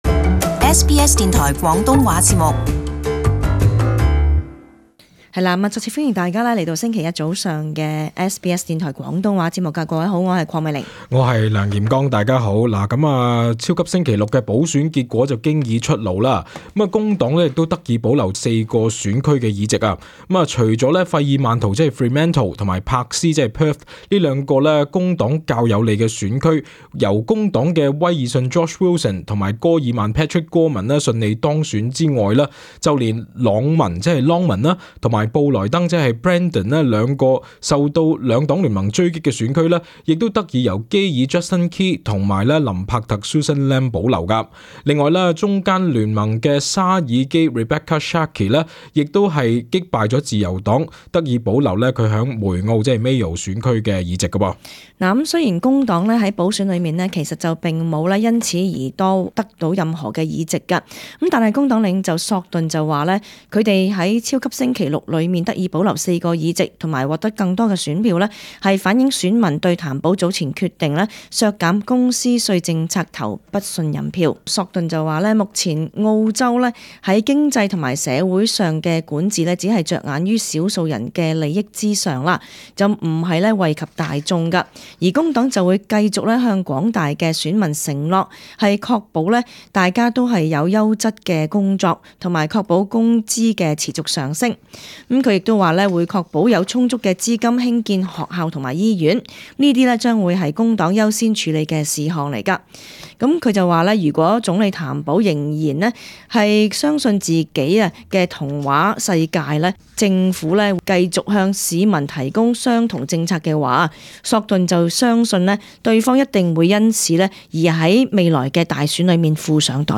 【時事報導】譚保淡化超級星期六補選結果